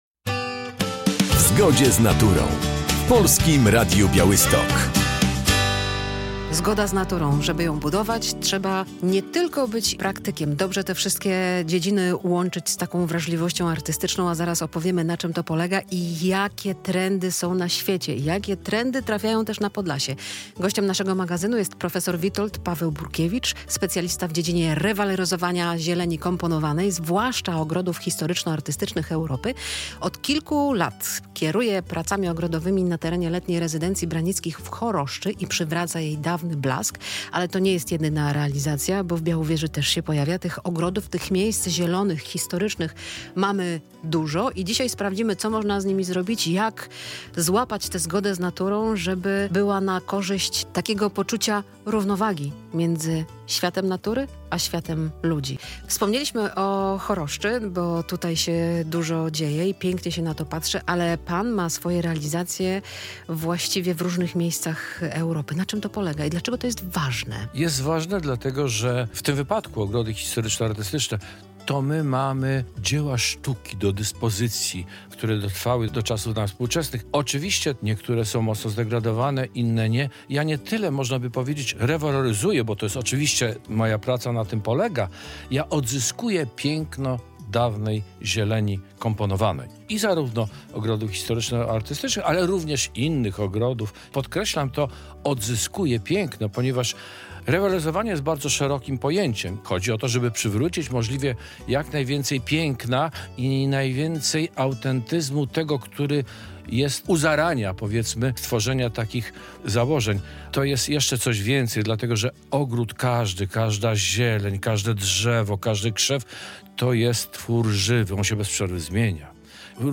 Rozmowa o zielonej scenografii i łączności z naturą